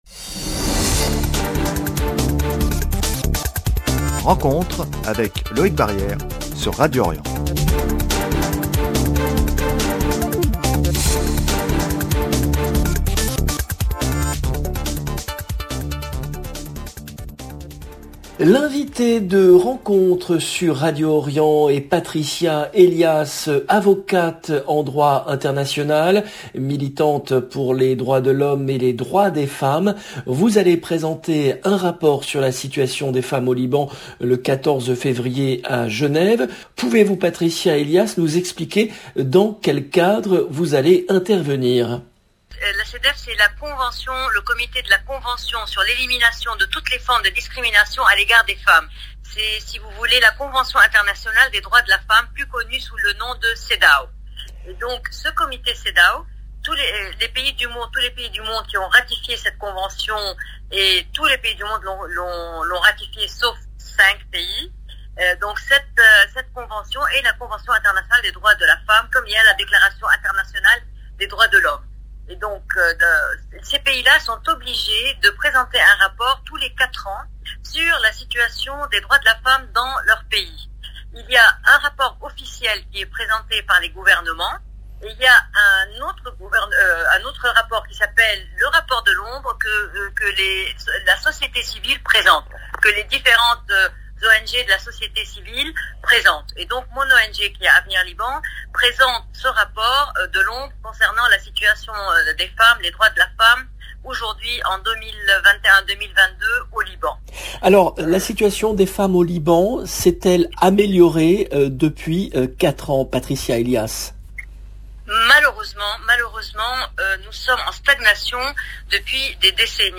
Elle va présenter un rapport sur la situation des femmes au Liban le 14 février à Genève. Emission diffusée le samedi 29 janvier 2022 0:00 14 min 5 sec